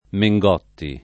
[ me jg0 tti ]